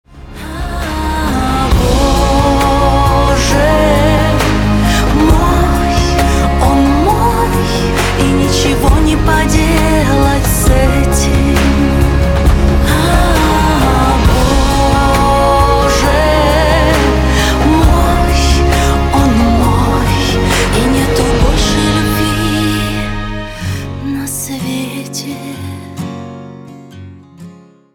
• Качество: 192, Stereo
гитара
спокойные
красивый женский вокал
нежные
эстрадные